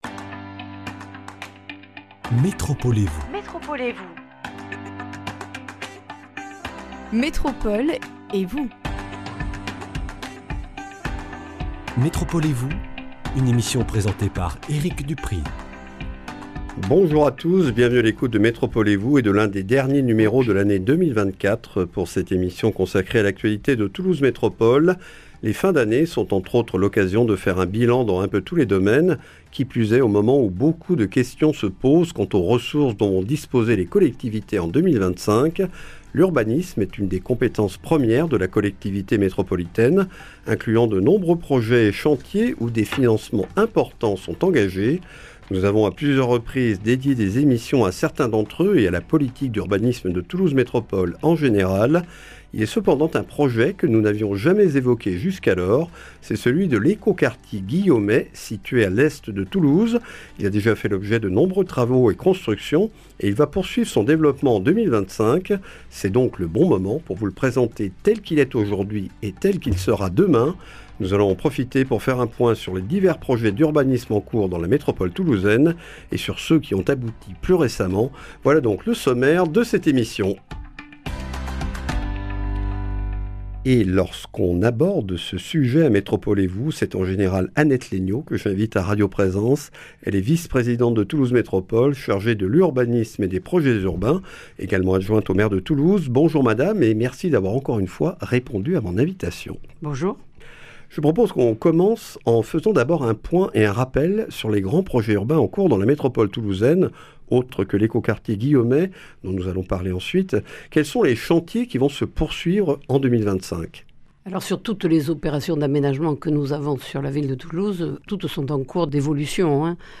À l’est de Toulouse, l’éco-quartier Guillaumet poursuit son développement courant 2025. Lors de ce numéro, nous faisons le point sur ce projet et les autres chantiers urbains en cours dans la Métropole avec Annette Laigneau, adjointe au maire de Toulouse, vice-présidente de Toulouse Métropole chargée de l’Urbanisme et des Projets urbains.